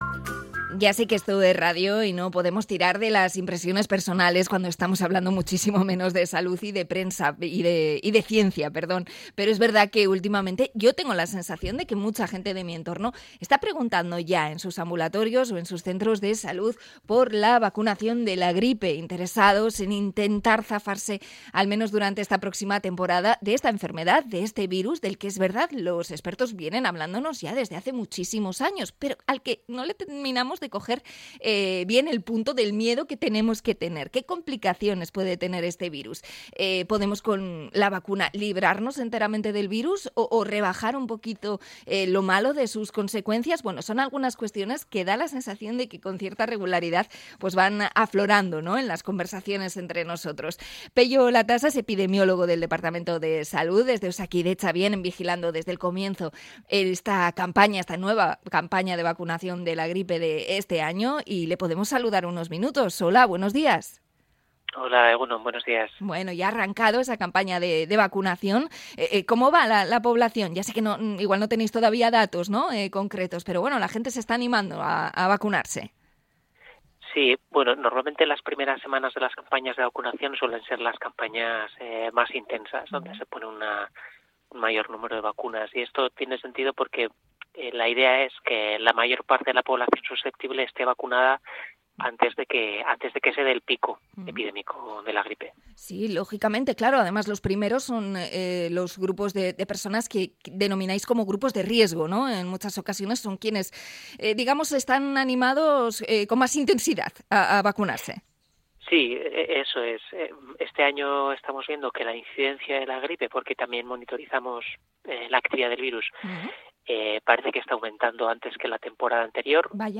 Entrevista sobre la campaña de vacunación de la gripe